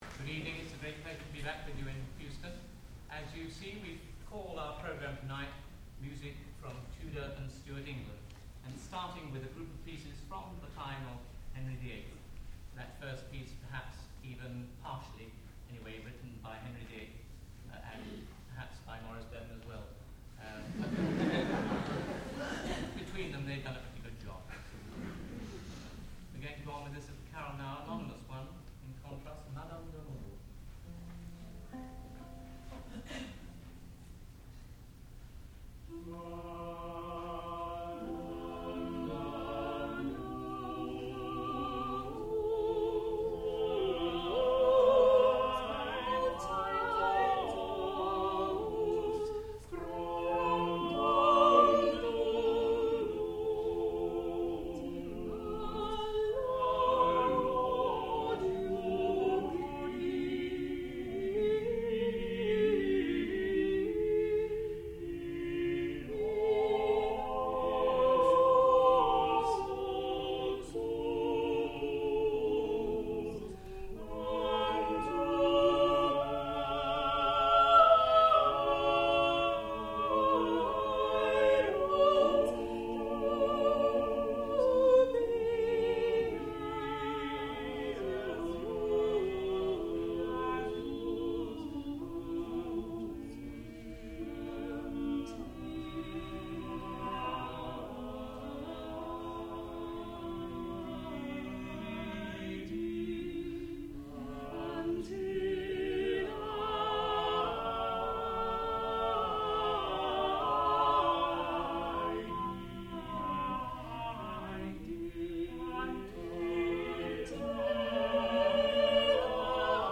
sound recording-musical
classical music
tenor
soprano
lute